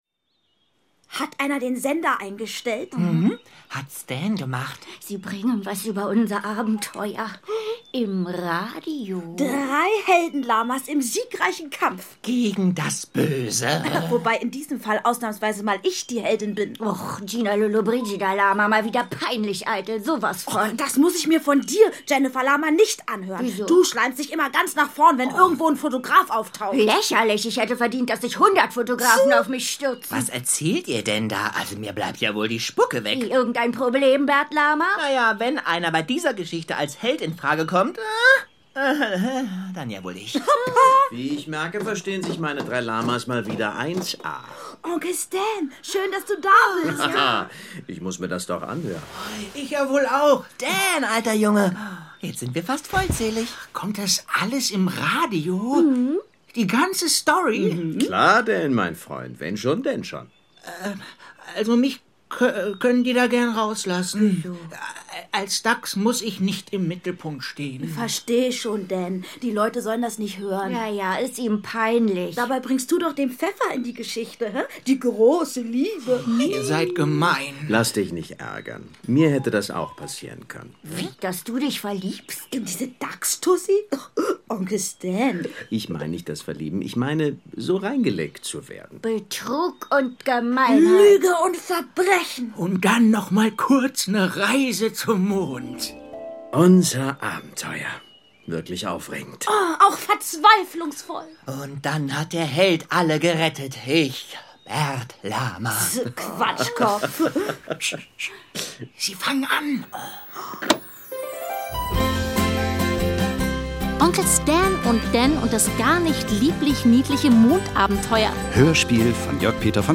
Kinderhörspiel: Onkel Stan und Dan und das gar nicht lieblich-niedliche Mondabenteuer (Teil 1) ~ Hörspiele, Geschichten und Märchen für Kinder | Mikado Podcast